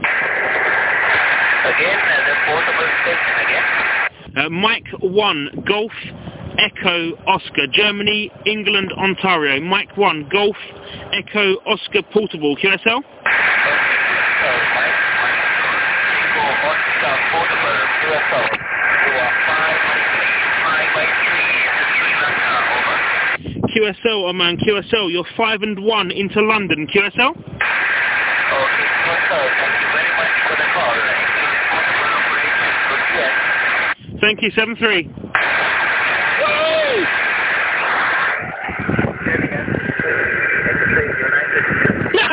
QSO Recordings